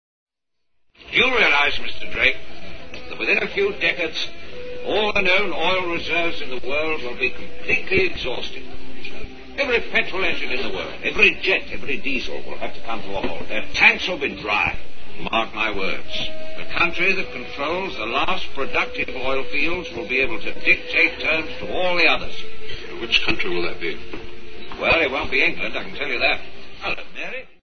April 20, 2003, 2100 PDT (FTW) – In the episode "Yesterday's Enemies" of the TV spy thriller Secret Agent (Danger Man) starring Patrick McGoohan, is incredible dialogue regarding the end of the age of oil.
Starring: Patrick McGoohan